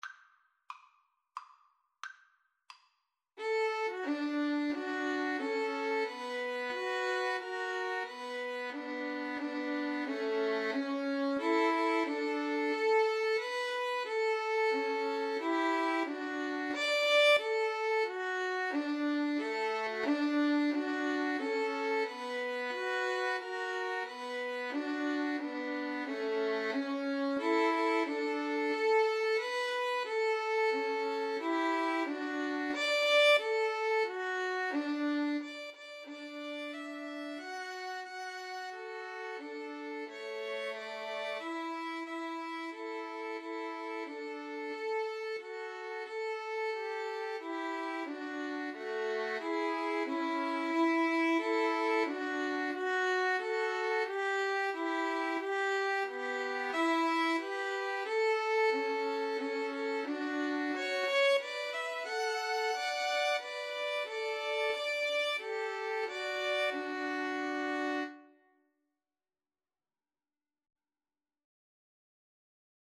D major (Sounding Pitch) (View more D major Music for Violin Trio )
Maestoso = c.90
Traditional (View more Traditional Violin Trio Music)
star_spangled_banner_3VLN_kar1.mp3